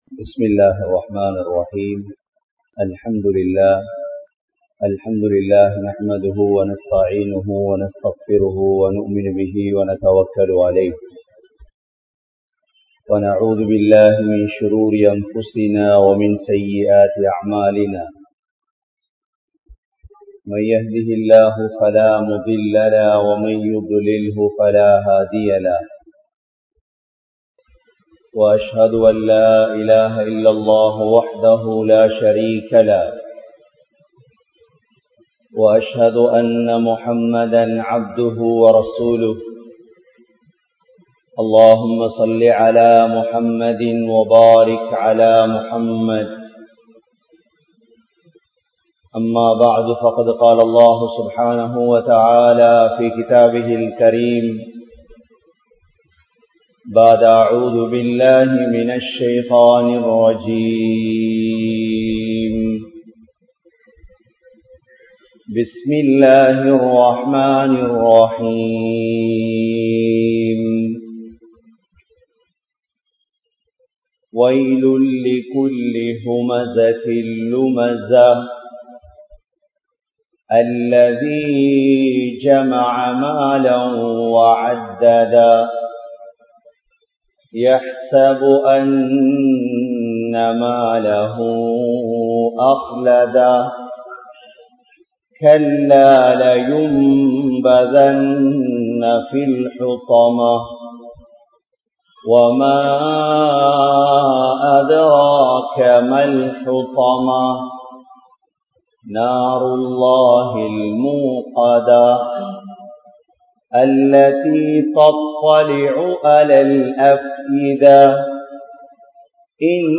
Soorathul Humaza(சூரத்துல் ஹுமஸா) | Audio Bayans | All Ceylon Muslim Youth Community | Addalaichenai
Grand Jumua Masjith